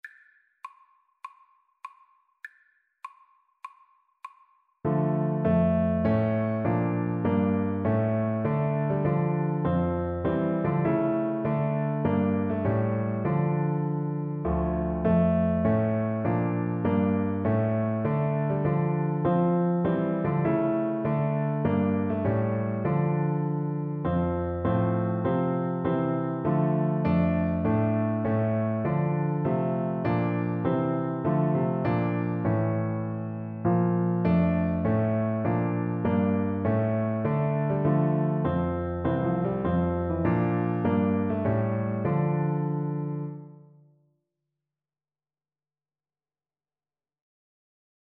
Flute
D minor (Sounding Pitch) (View more D minor Music for Flute )
4/4 (View more 4/4 Music)
D6-Bb6
Classical (View more Classical Flute Music)